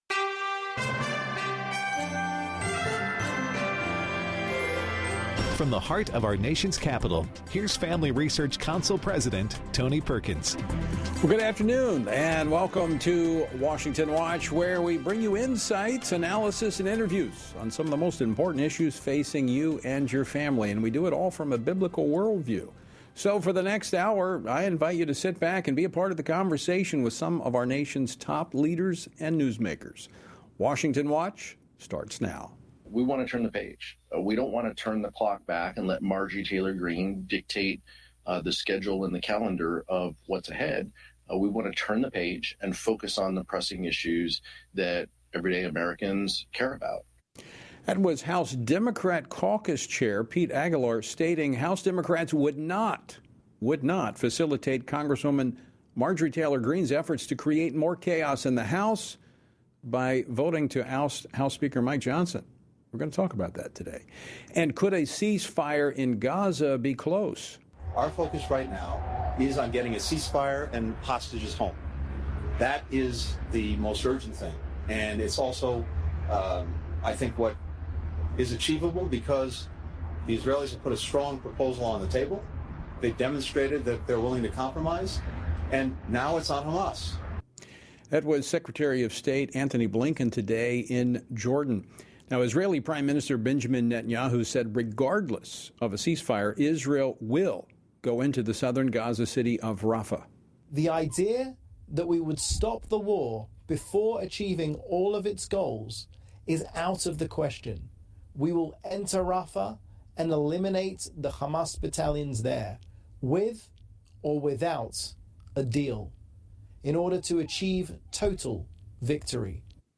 On today’s program: Pete Ricketts, U.S. Senator for Nebraska, discusses the latest news regarding Israel’s war against Hamas, responds to threats from the Chinese Communist Party following U.S. military aid to Taiwan, and sets the record straight regarding the Biden administration’s electric vehicle mandate. Liz Murrill, Louisiana Attorney General, explains why Louisiana has joined the growing list of states suing the U.S. Department of Education for their revision of Title IX rules, which include “gender identity” as a protected category. Chris Smith, U.S. Representative for the 4th District of New Jersey, reacts to House Democrats’ decision to support House Speaker Mike Johnson and sheds light on efforts to stop the World Health Organization’s proposed Pandemic Preparedness Agreement.